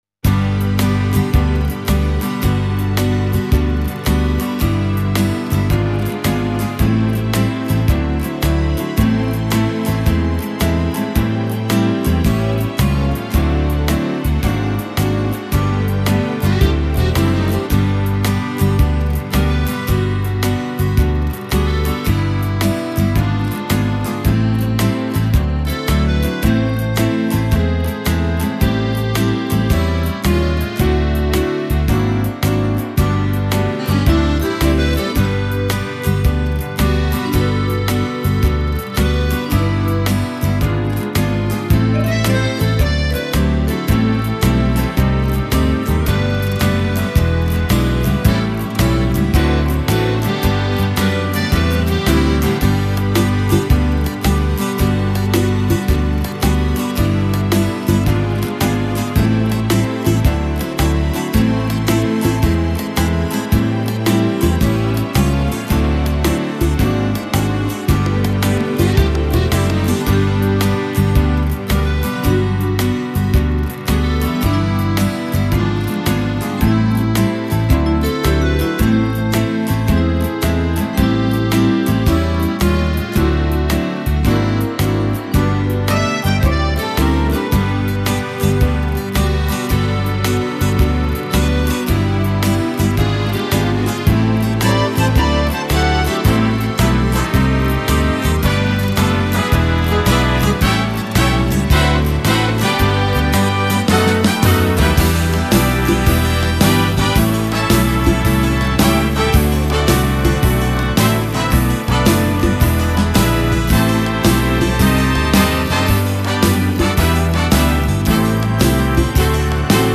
Genere: Moderato
Scarica la Base Mp3 (3,57 MB)